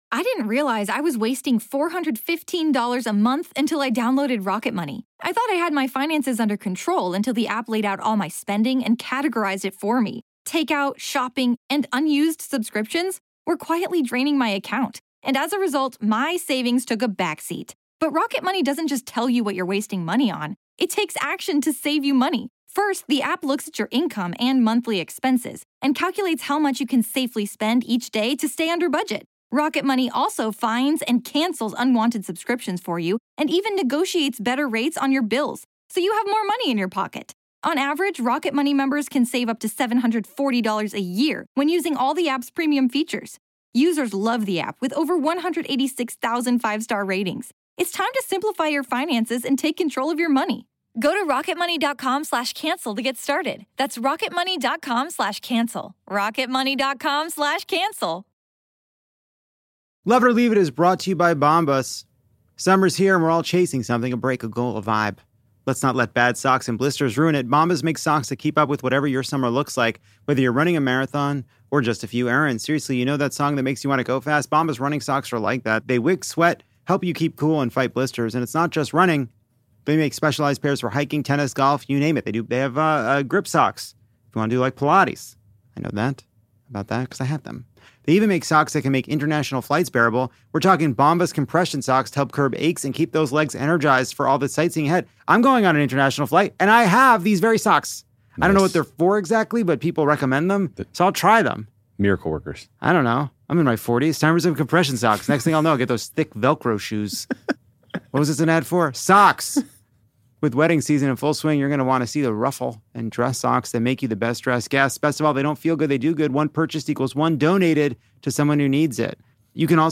Join us as Lovett or Leave It journeys to the Great White North, where the kisses are French and the bacon ham, for Montreal’s Just For Laughs Festival.